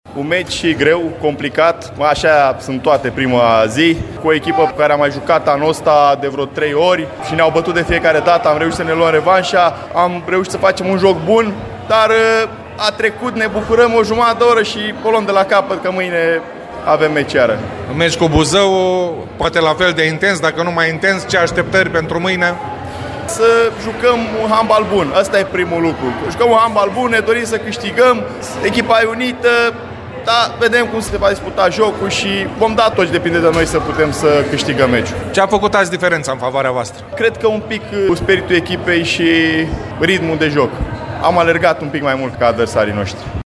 Într-un interviu